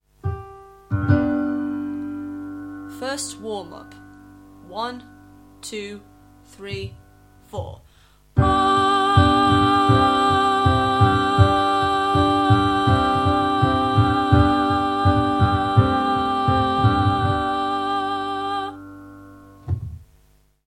1. Start off getting the choir to do a long “ah” in unison on a comfortable G.
Playing a mixture of related chords underneath in steady rhythm is a good way to help them keep listening and retain their pitching.
Exercise: all together